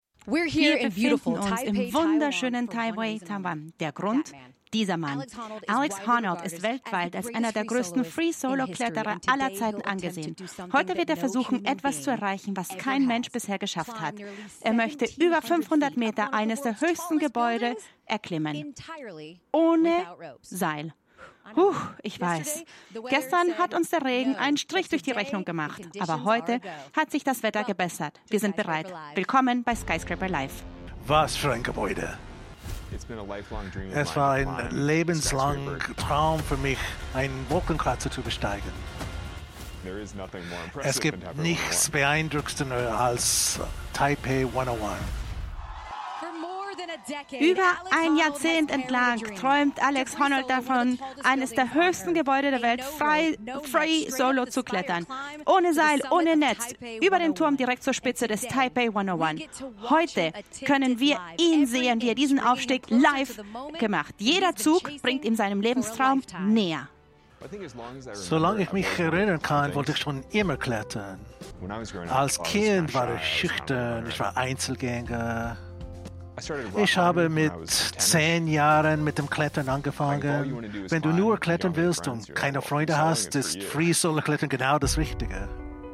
aber hier habe ich etwas, was dem zumindest klanglich nahekommt.
Es ist kein Synchron, sondern Simultandolmetschen, weil es ursprünglich ein Livestream war.
Trotzdem klingt es durch den Einsatz von offenbar Amerikadeutschen, die sowohl starke Ami-Lautung als auch starken Dialekt-Einschlag und einige Syntax-Fehler doch, nun, interessant.
SkyscraperLive.mp3